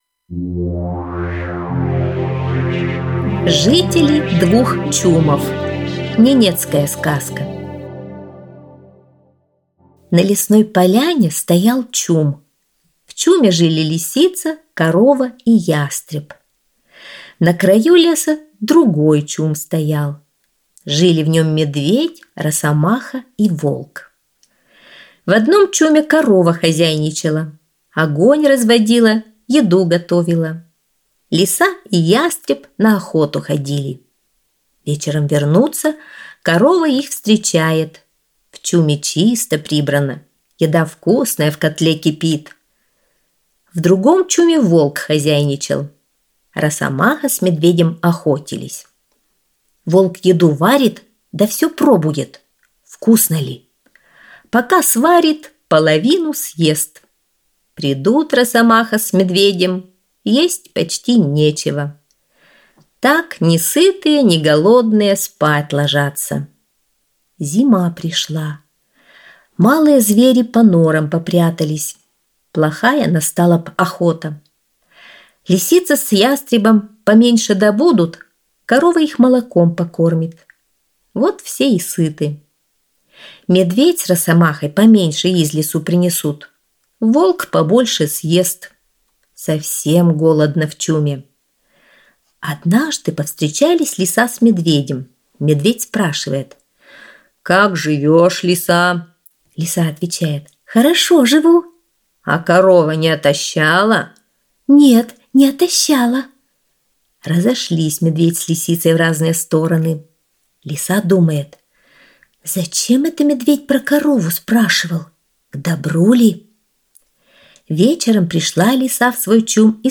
Жители двух чумов - ненецкая аудиосказка - слушать онлайн